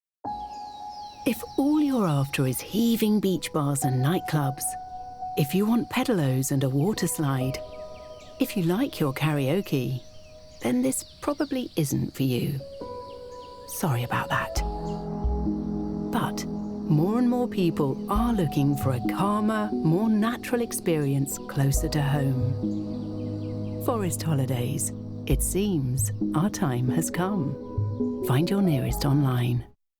Engels (Brits)
Commercieel, Warm, Veelzijdig, Vriendelijk, Zakelijk
Commercieel